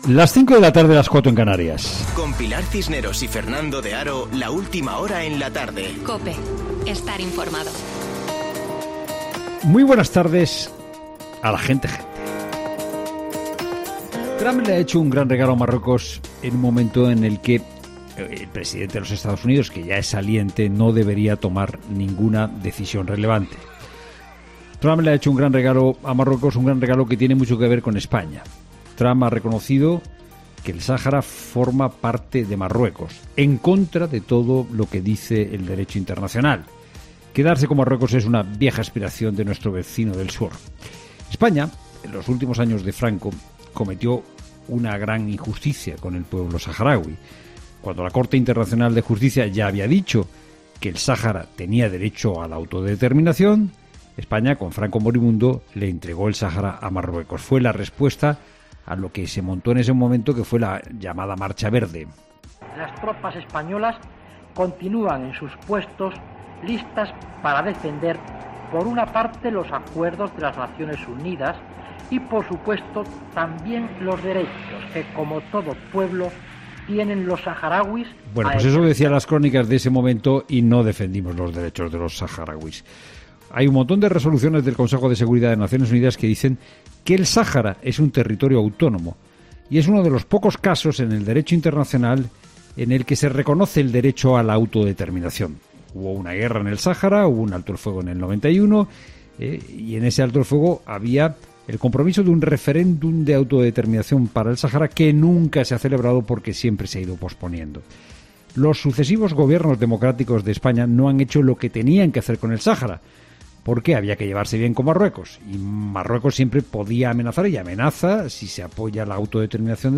Boletín de noticias COPE del 16 de diciembre de 2020 a las 17.00 horas